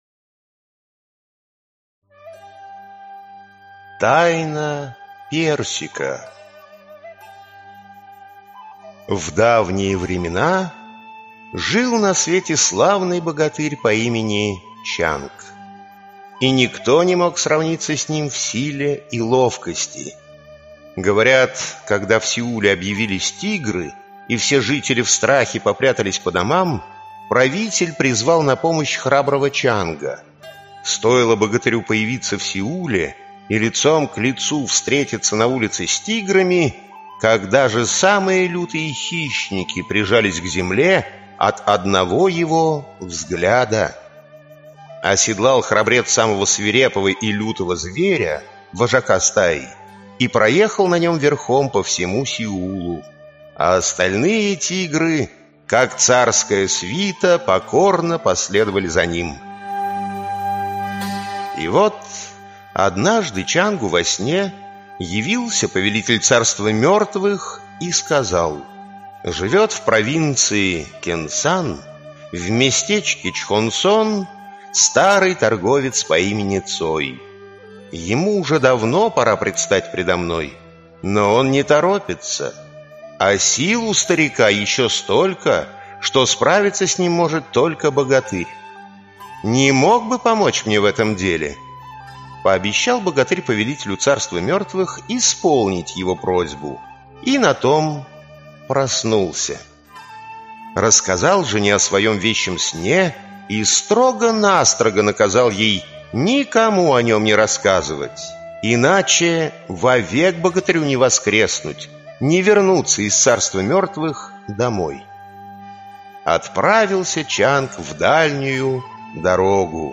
Аудиокнига Волшебные сказки Страны Утренней Свежести. Корейские сказки | Библиотека аудиокниг